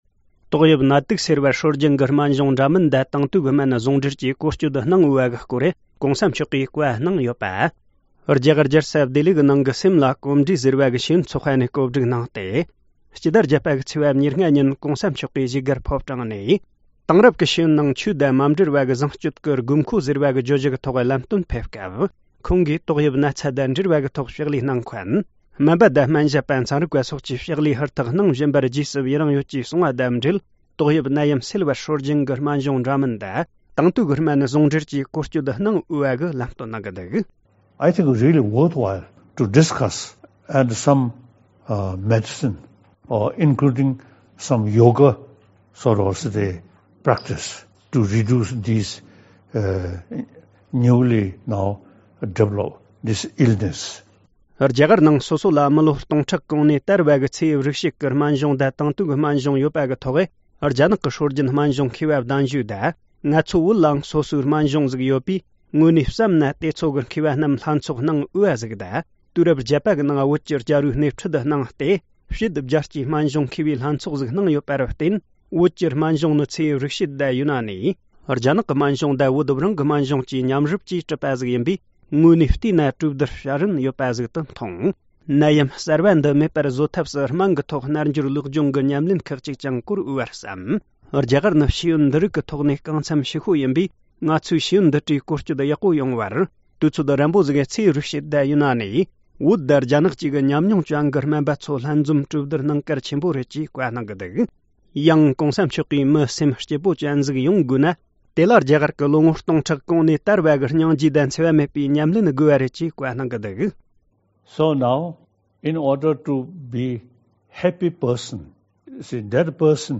སྤྱི་ནོར་༧གོང་ས་མཆོག་གིས་དྲ་ལམ་བརྒྱུད་བཀའ་སློབ་སྩལ་སྐབས། ༢༠༢༠།༨།༢༥ སྤྱི་ནོར་༧གོང་ས་མཆོག་གིས་དྲ་ལམ་བརྒྱུད་བཀའ་སློབ་སྩལ་སྐབས། ༢༠༢༠།༨།༢༥
སྒྲ་ལྡན་གསར་འགྱུར། སྒྲ་ཕབ་ལེན།